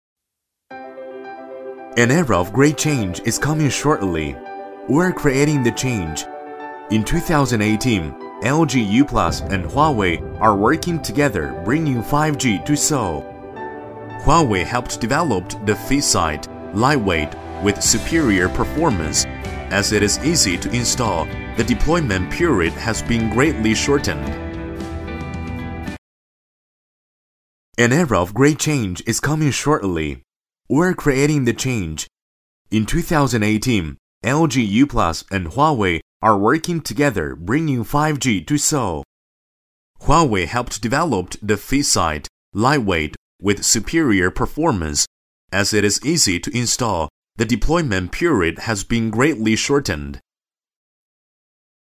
男40号配音师
十余年从业经验，精通中文，日文，英文，声音浑厚，庄重，大气。
英文-男40-华为huawei 专题.mp3